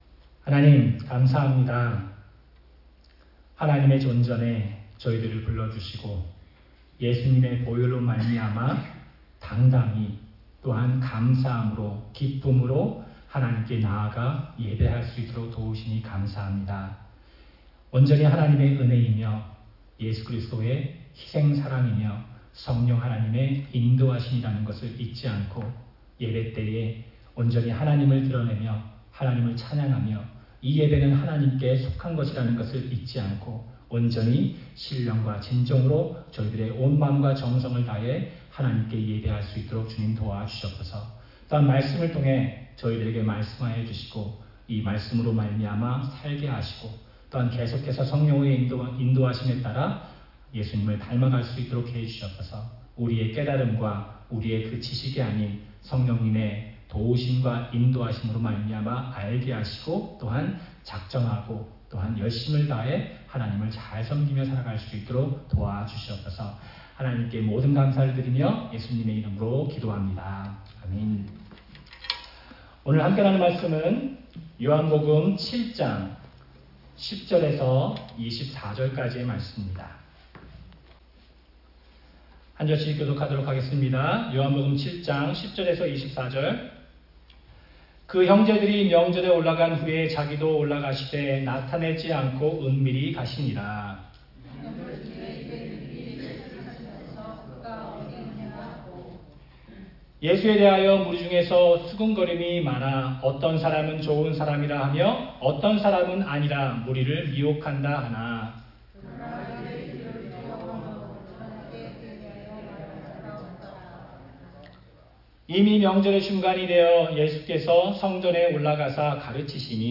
주일 설교
5월-26일-주일-설교.mp3